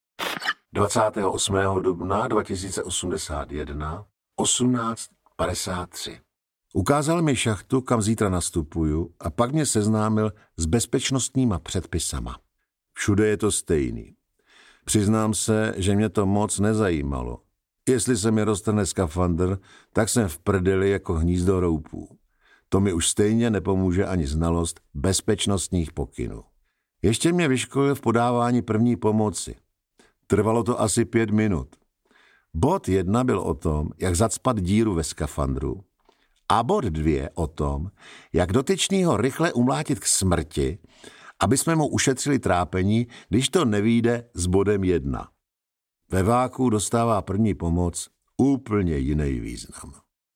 Audiokniha Měsíční deník je autentický deník zachráněný součinem vskutku absurdních okolností, ve kterých hraje roli obyčejný rohlík, neviditelný mravenec, kapesní stroj času a spousta dalších předmětů, jež se normálně válejí na Měsíci.